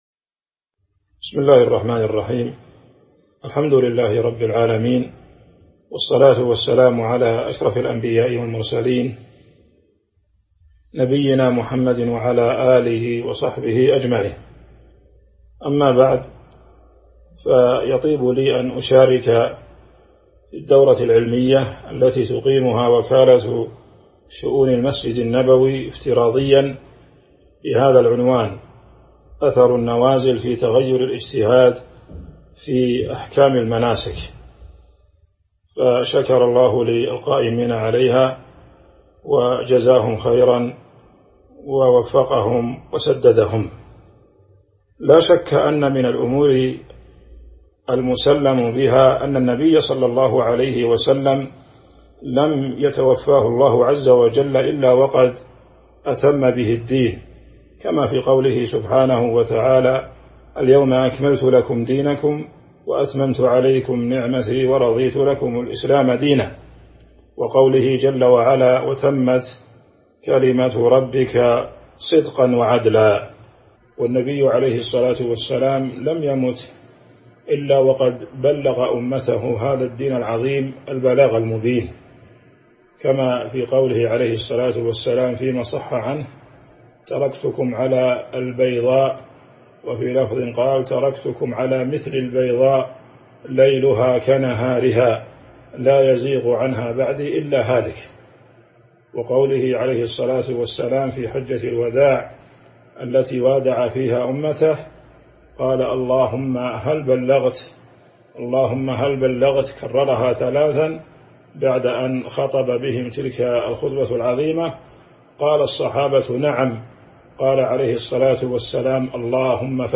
تاريخ النشر ٥ ذو الحجة ١٤٤١ هـ المكان: المسجد النبوي الشيخ